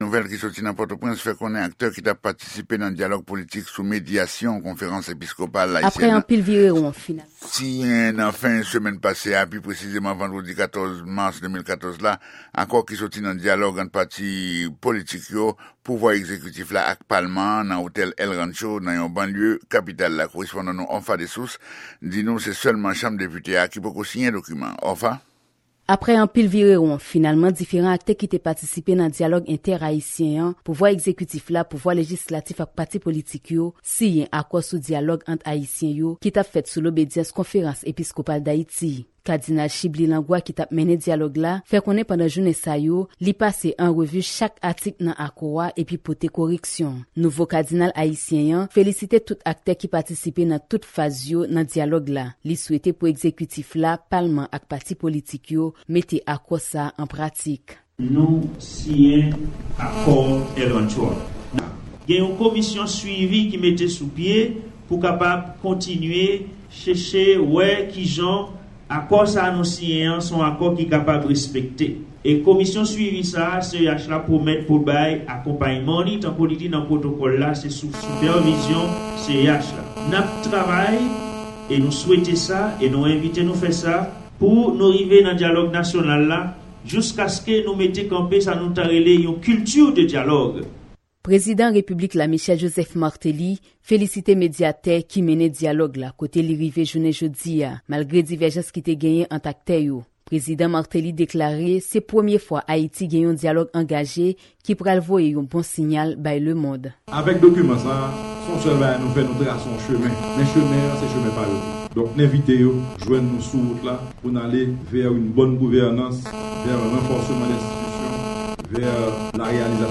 Repòtaj : Siyati Akò sou Dyalòg Entè-Ayisyen an Kòmantè ak Reyaksyon